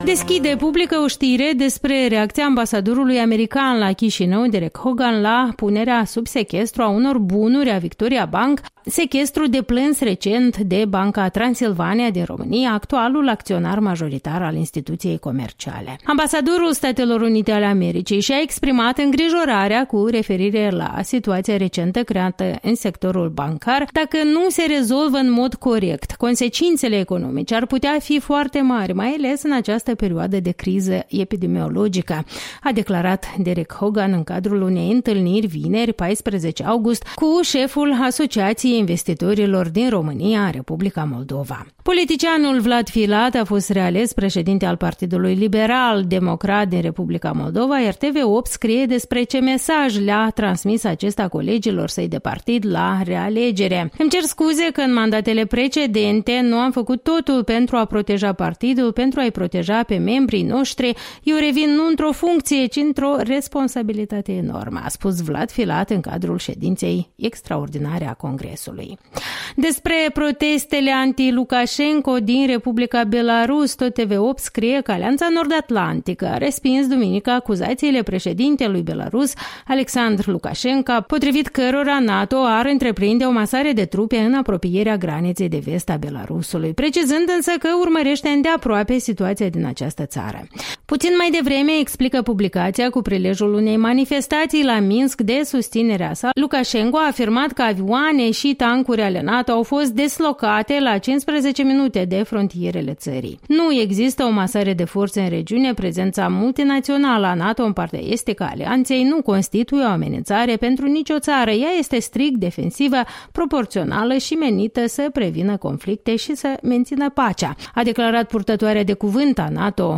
Revista matinală a presei.